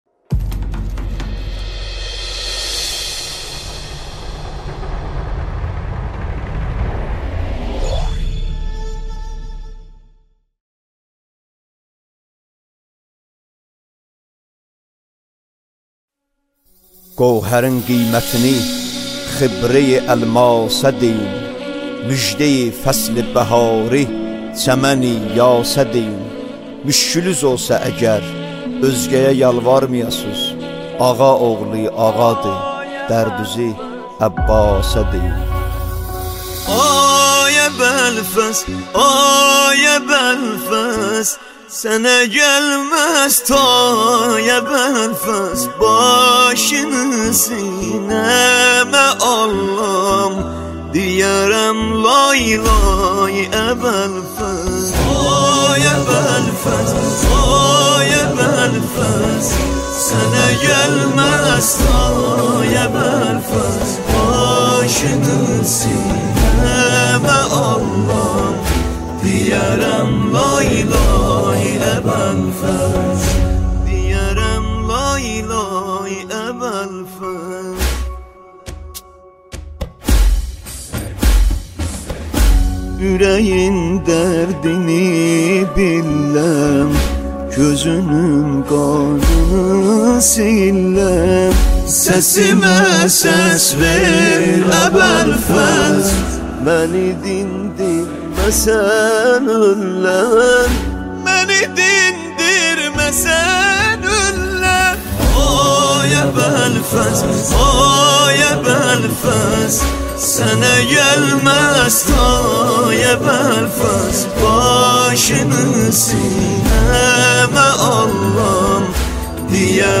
نماهنگ دلنشین ترکی